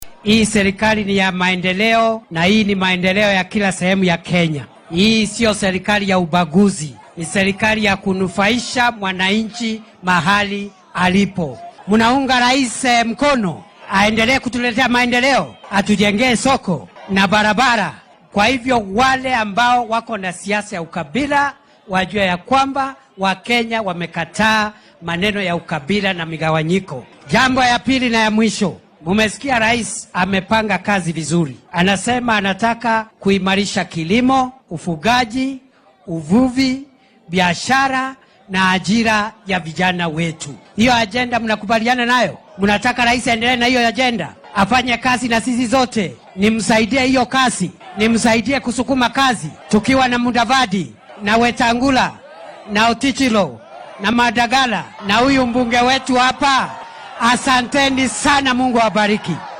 Xilli uu ka qayb galay munaasabad lagu dhagax-dhigayay dhismaha suuqa casriga ah ee Luanda ee ismaamulka Vihiga ayuu Kindiki hoosta ka xarriiqay in maamulka talada waddanka haya ee Kenya Kwanza ay ka go’an tahay dardargelinta horumarka iyo wanaajinta nolosha kenyaanka.